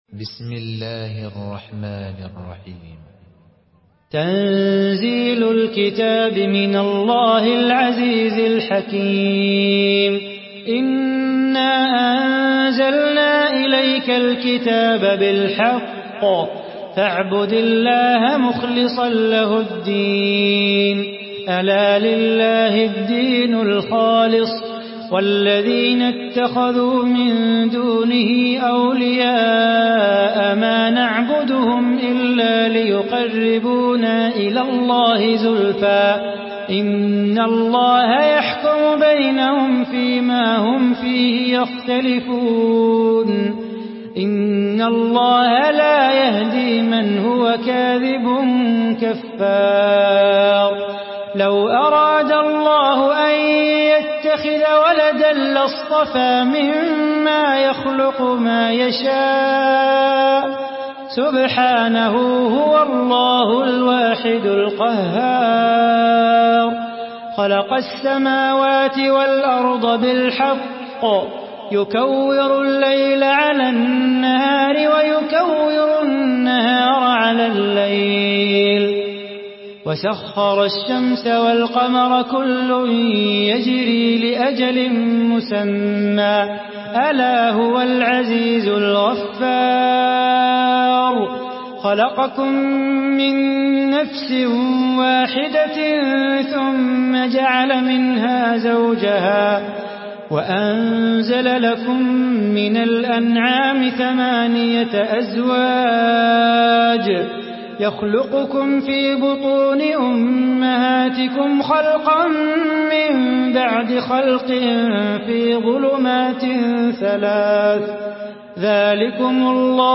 Surah Zümer MP3 by Salah Bukhatir in Hafs An Asim narration.
Murattal Hafs An Asim